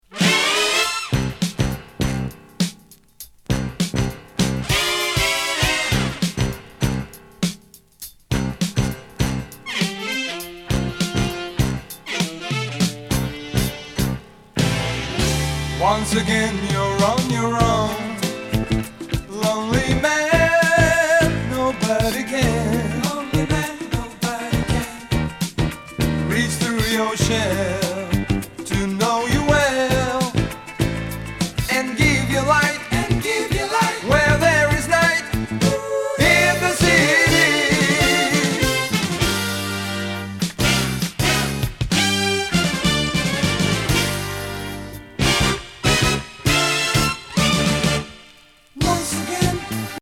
メロウ・ソウルフル・グルーヴ！